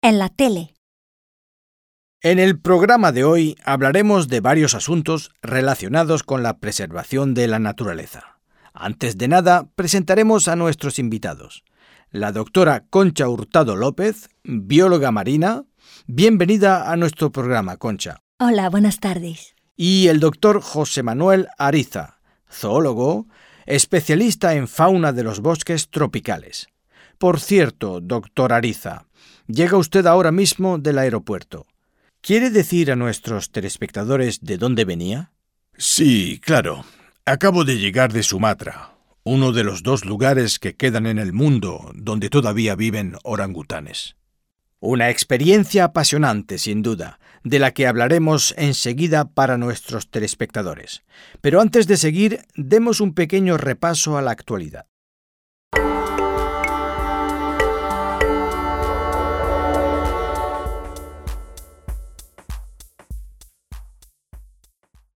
Dialogue - En la tele